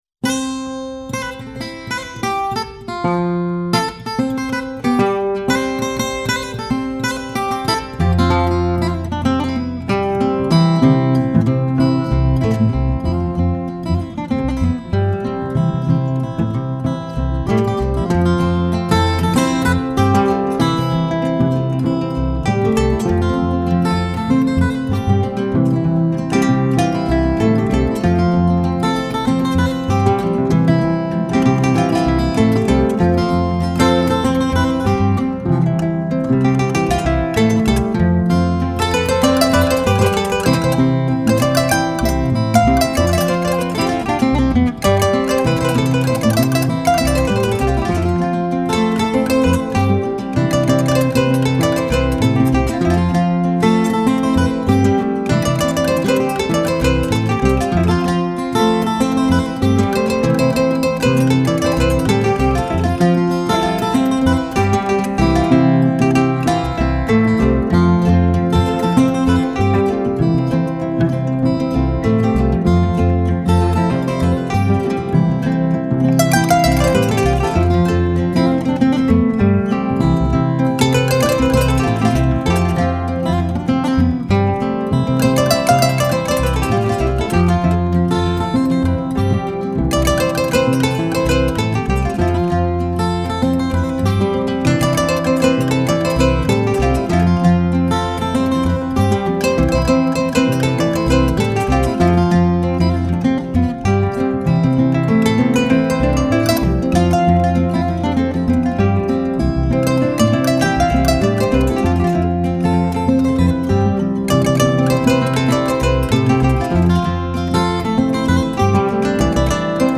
建构成这张忧美动人、充满意象的经典专辑。